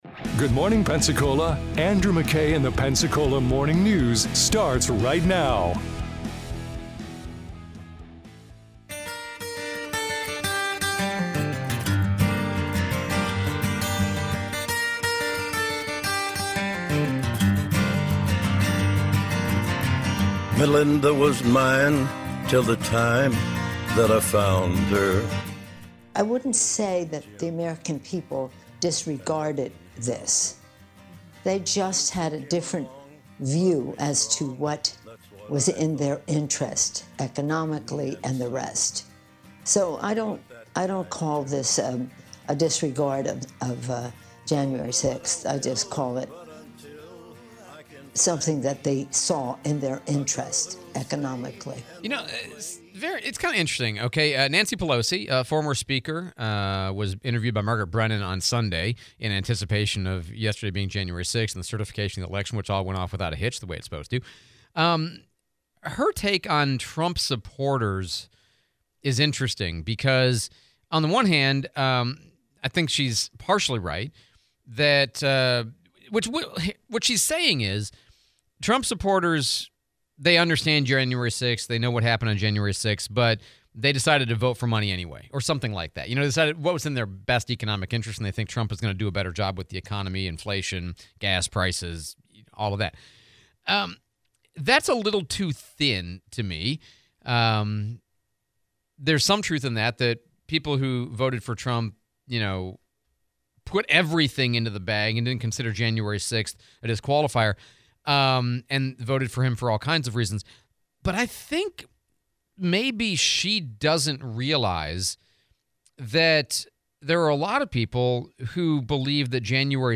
Pelosi interview, Topic: Tap or swipe?